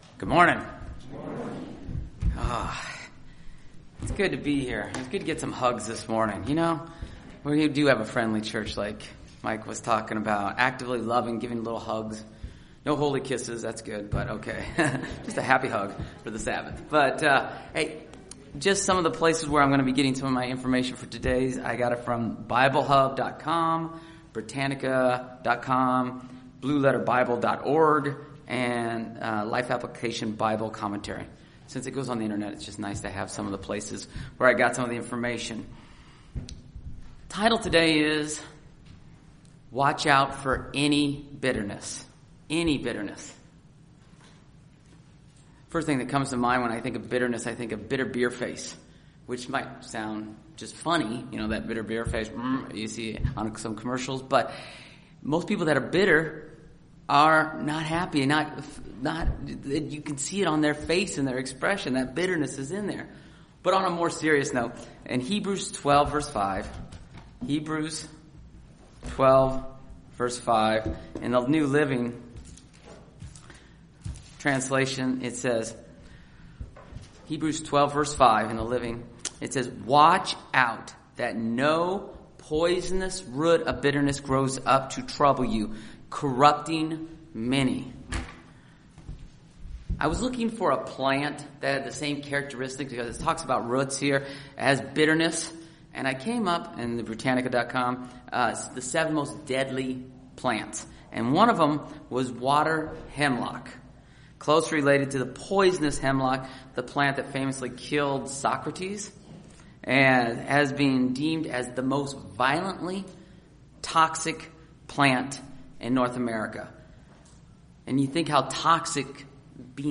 God tells us that we must guard against any root of bitterness. This sermon explains what bitterness is and gives a biblical example of what happens when bitterness takes over a life.